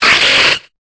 Cri de Ténéfix dans Pokémon Épée et Bouclier.